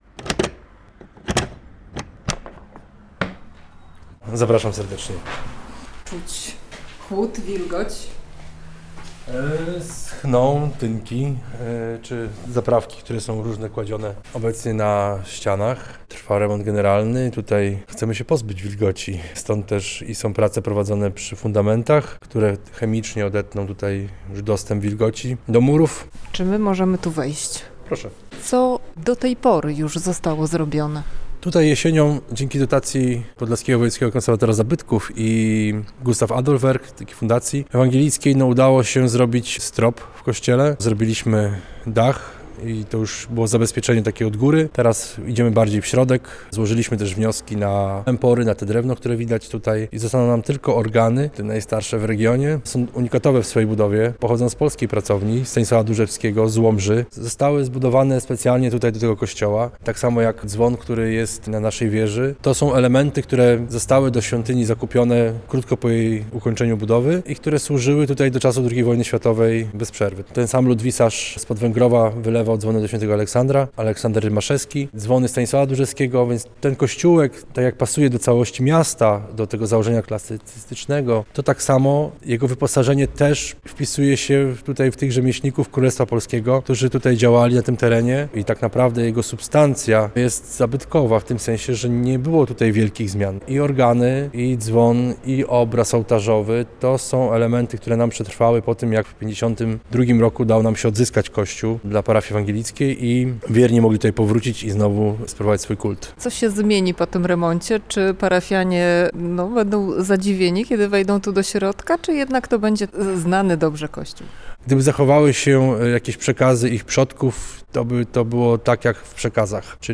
Remont kościoła ewangelicko-augsburskiego w Suwałkach - Relacja